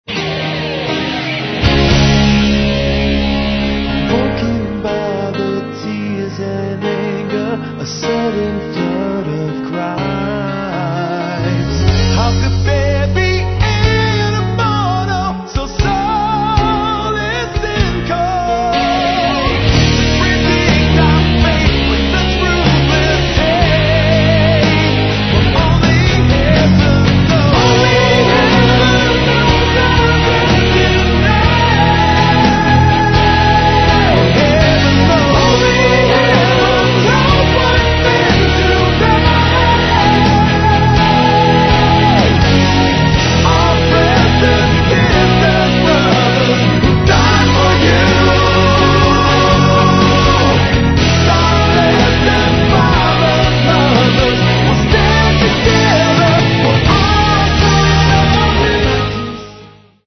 Рок
vocals
drums
lead guitars
keyboards
bass & guitars